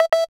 Movie_Shot_end1.wav